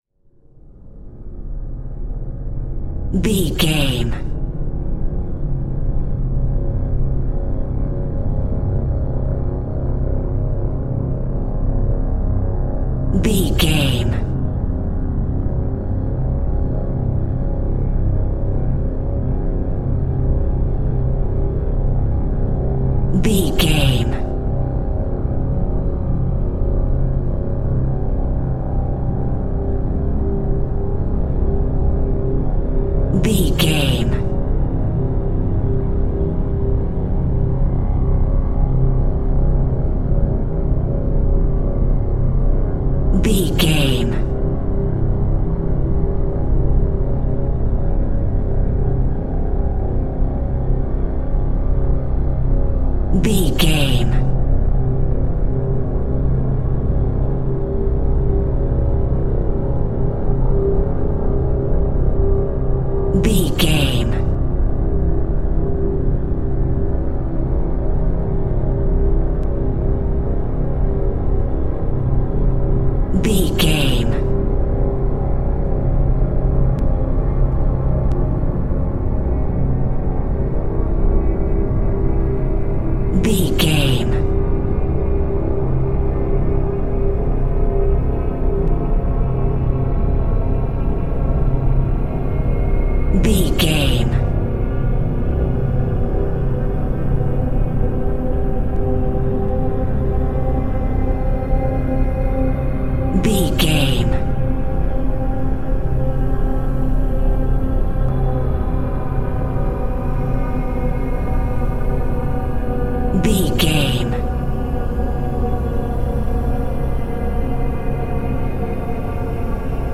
Aeolian/Minor
scary
ominous
dark
haunting
eerie
strings
synthesiser
suspenseful
mysterious